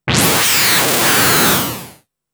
I had a go with Arp2600m, quite fun! Sounds like a SRR Cougar :laughing: